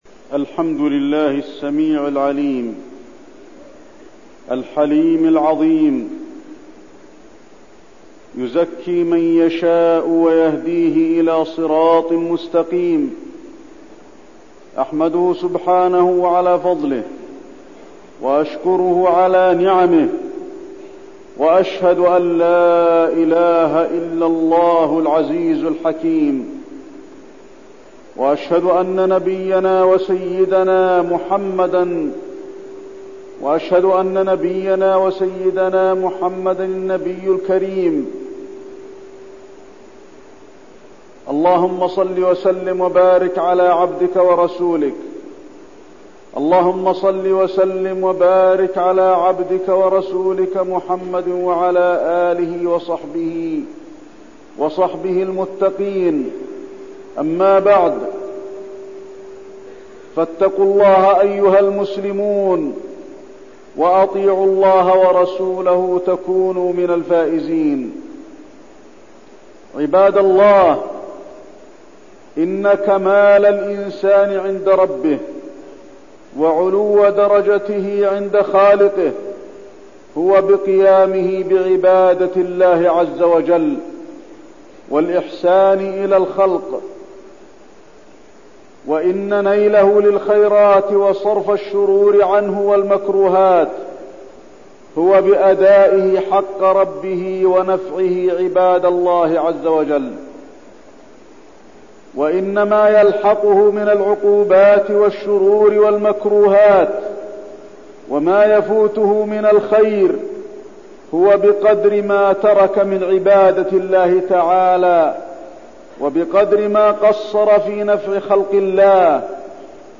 تاريخ النشر ١٧ شعبان ١٤٠٩ هـ المكان: المسجد النبوي الشيخ: فضيلة الشيخ د. علي بن عبدالرحمن الحذيفي فضيلة الشيخ د. علي بن عبدالرحمن الحذيفي الإحسان